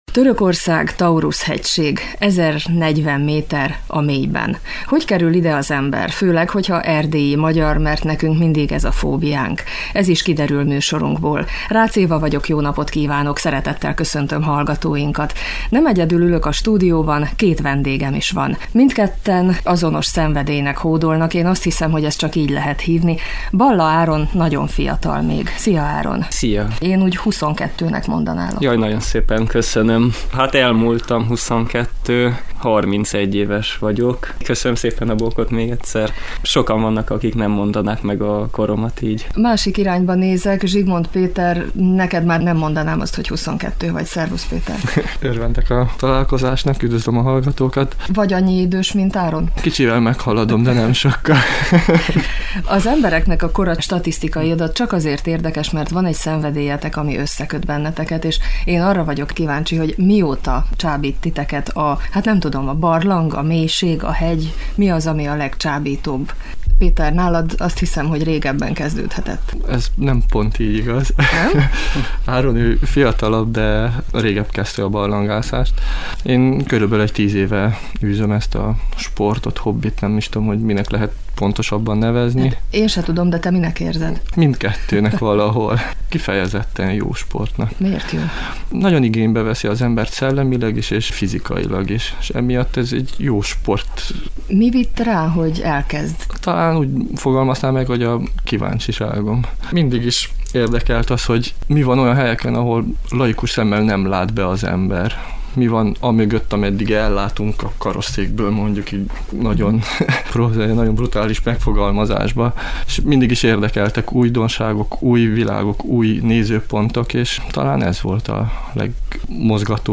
A teljes beszélgetés a lejátszóra kattintva meghallgatható. https